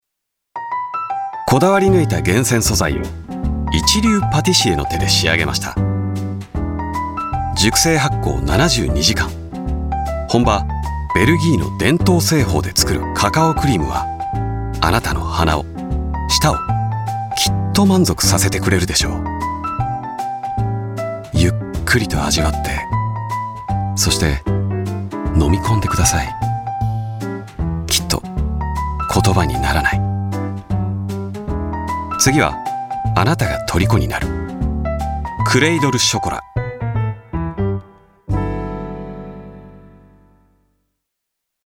CM